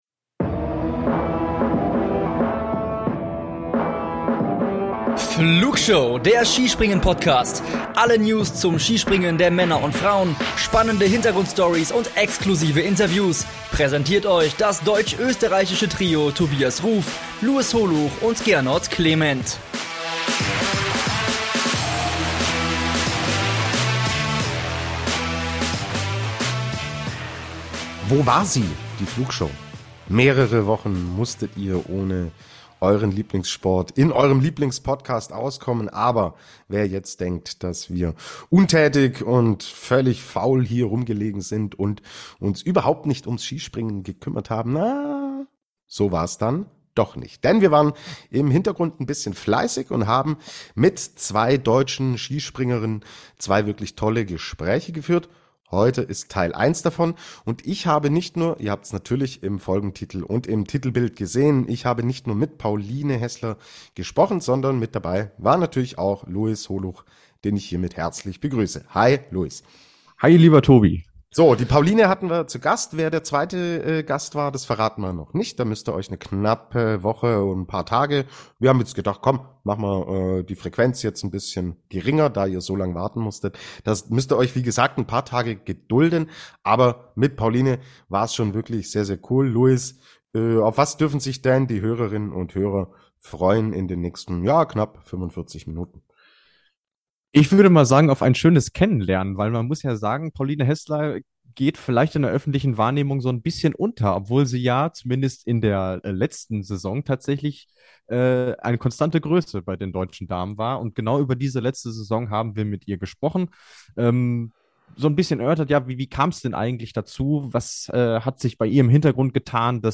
Interview ~ Wintersport Podcast